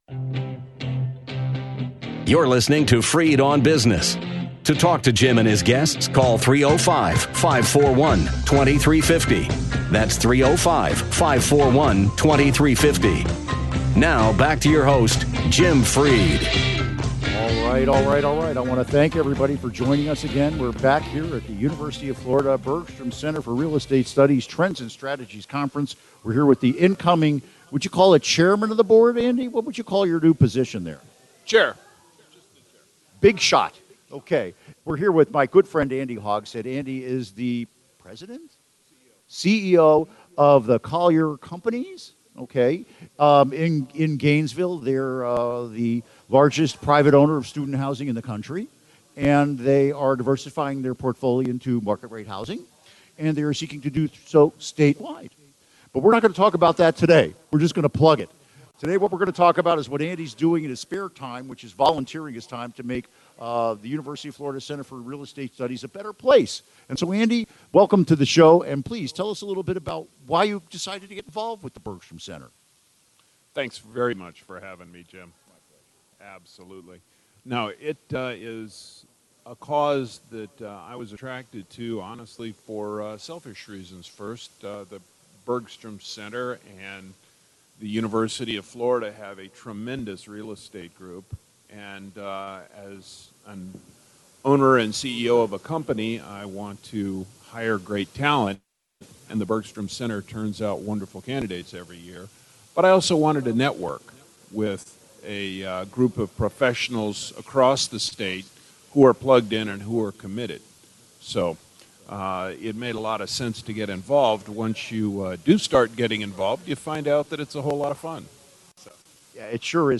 Interview Segment (To download, right-click […]